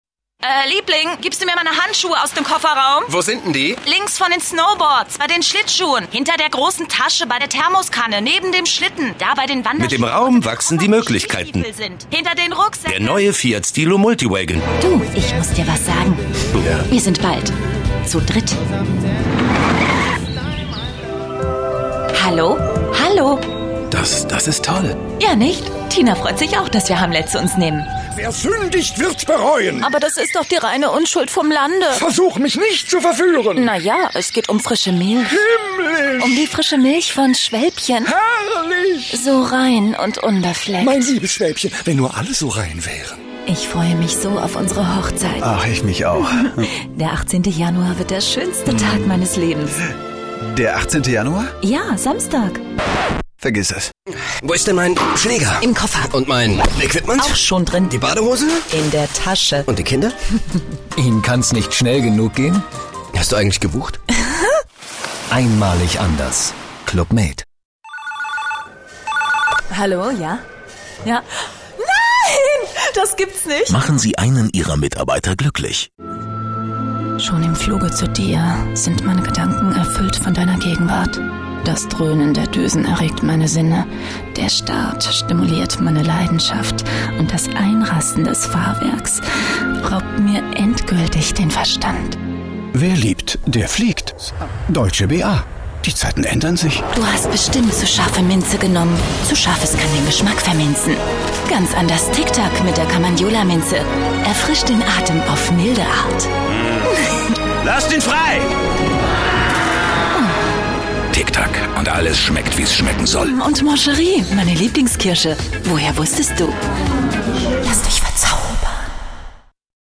Stimme: Warm, charismatisch, charmant.
Sprechprobe: eLearning (Muttersprache):